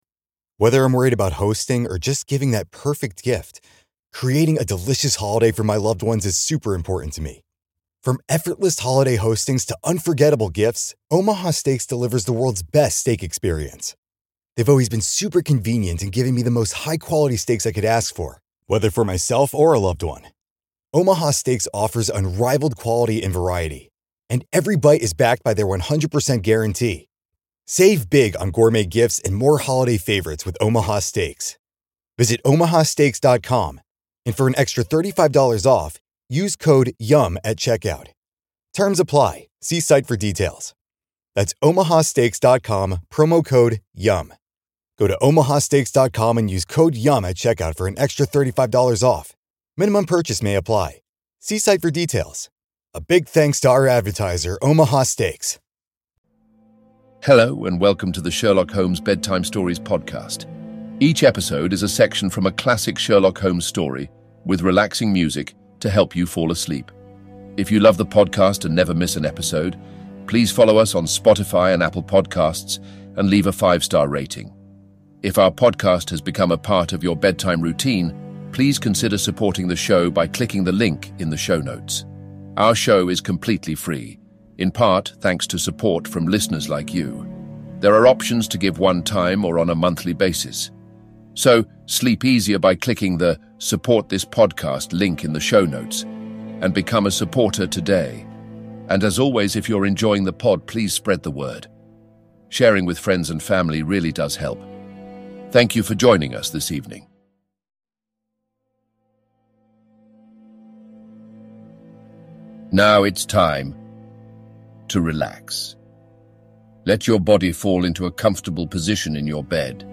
Fall asleep with your favorite comfort bedtime stories: the complete Sherlock Holmes in timeline order. In each episode, a section of a Sherlock Holmes story will be read aloud in a soothing text-to-speech voice set to relaxation music, to help you get to sleep.